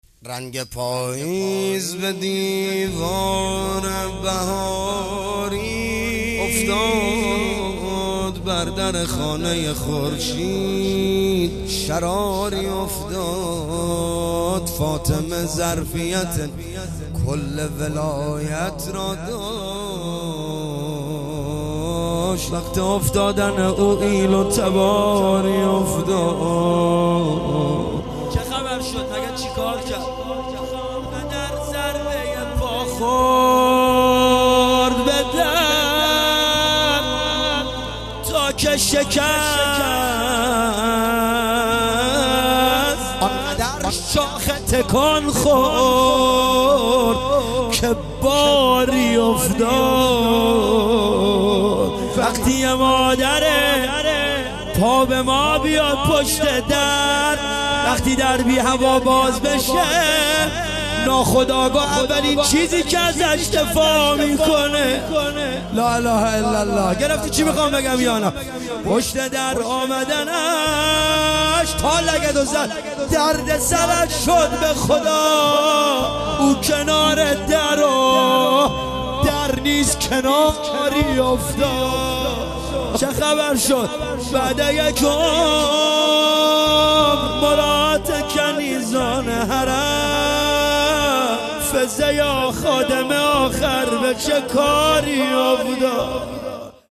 ایام فاطمیه 1399 | هیئت روضه الشهدا دزفول